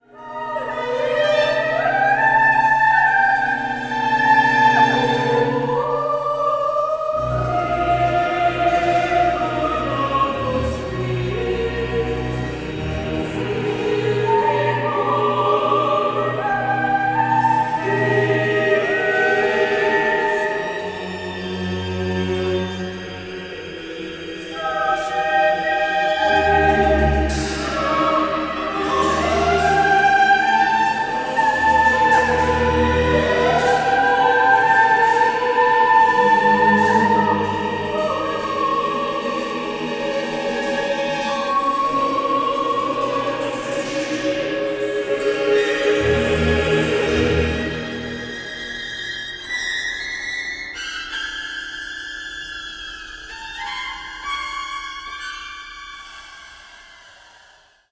countertenor
soprano
alto saxophone
positive organ
percussion